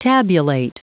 Prononciation du mot tabulate en anglais (fichier audio)
Prononciation du mot : tabulate